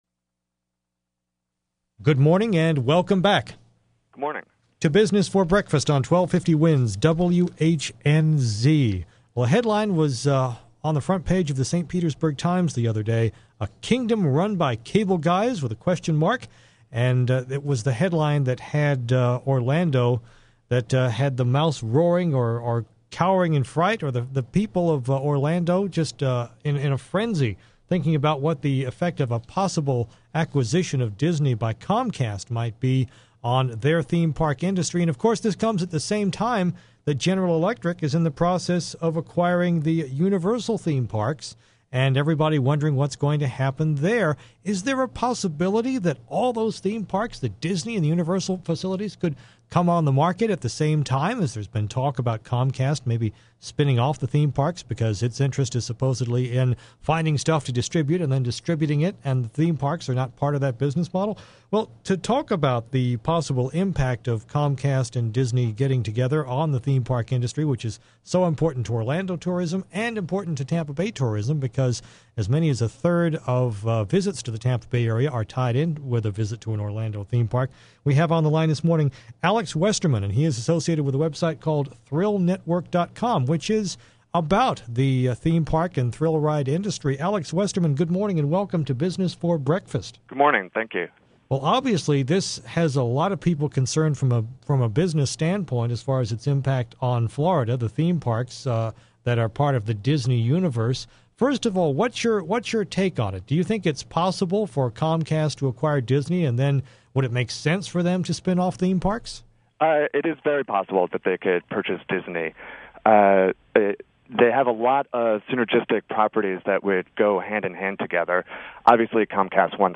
Interview on WHNZ